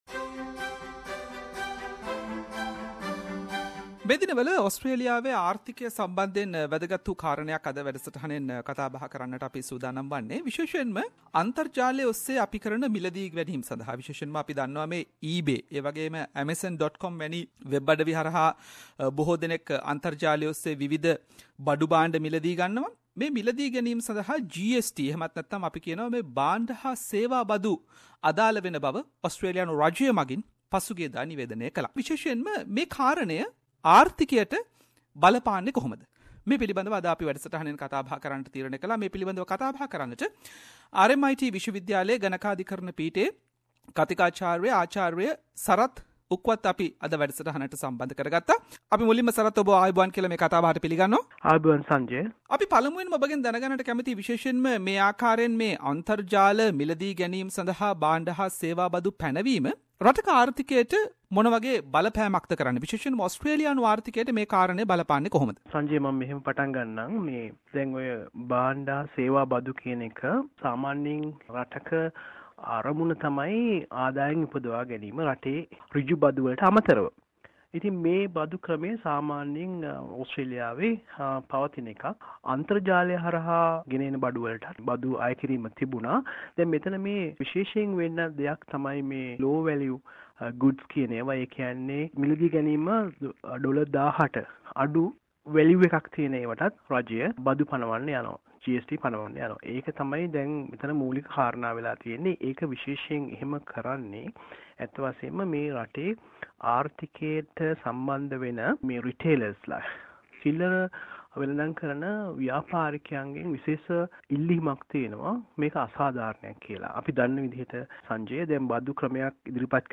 SBS Sinhala